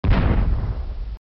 zdBong.MP3